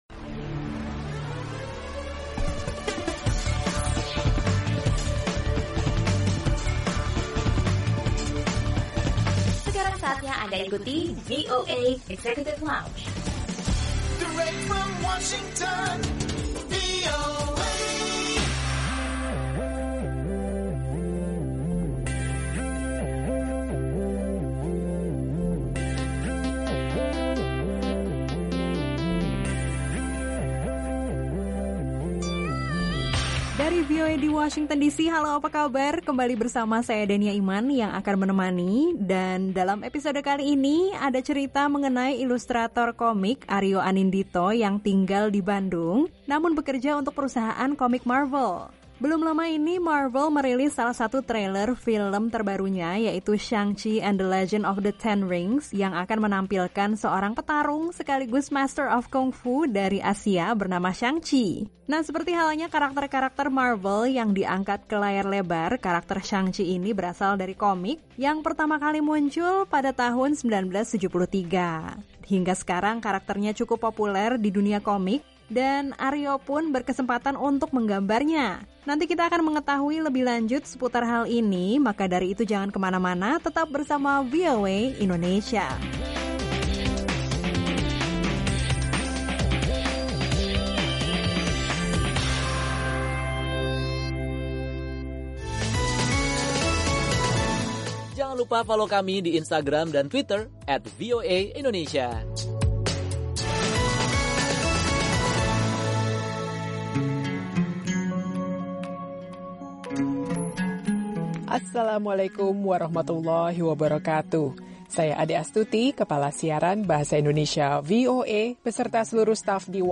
Dalam episode kali ini ada obrolan bersama ilustrator komik